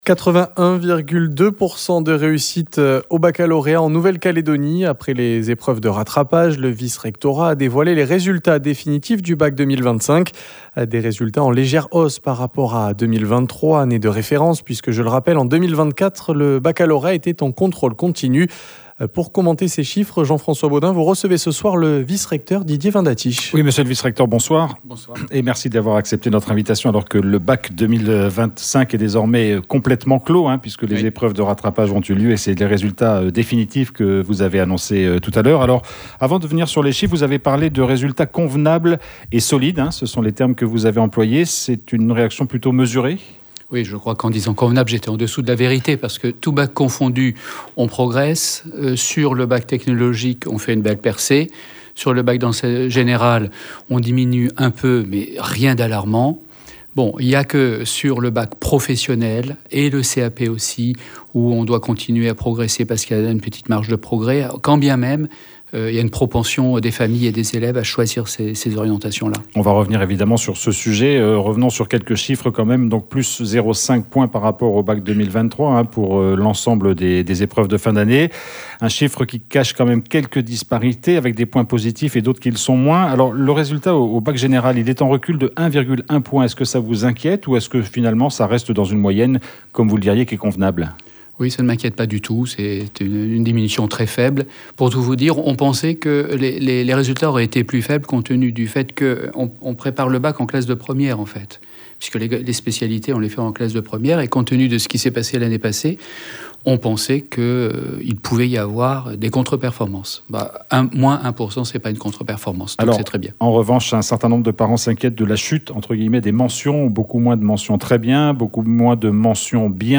Le vice-recteur, Didier Vin Datiche, était l'invité du journal, il a présenté et détaillé les chiffres du baccalauréat 2025 sur le territoire.